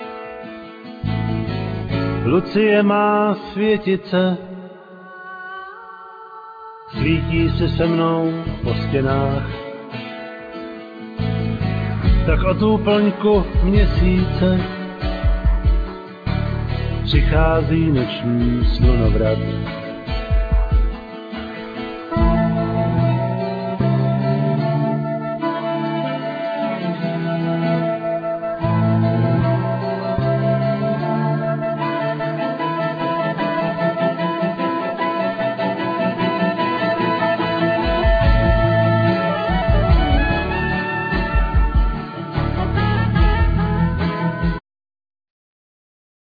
Vocal,Mandolin,E+A.Guitar
Saxophone,Clarinet,Whistle
Bass,Cello,Violin
Keyboards